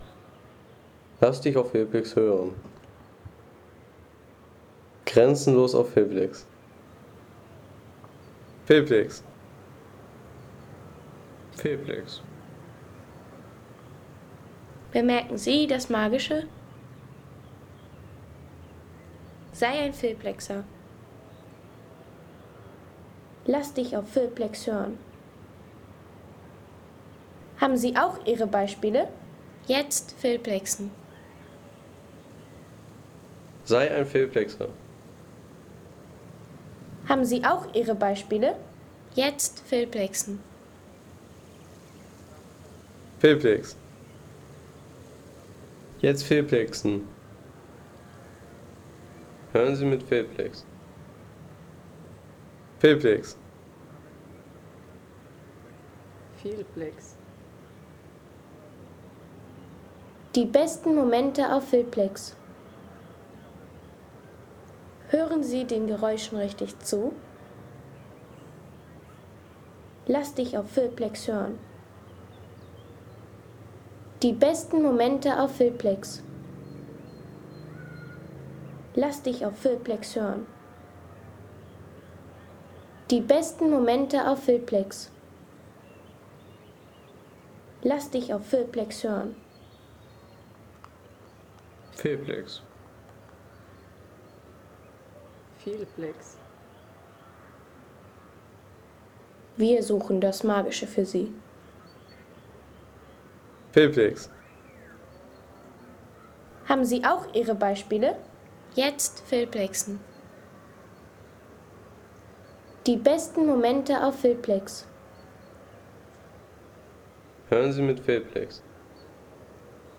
Umgebungsgeräusche an der Basteibrücke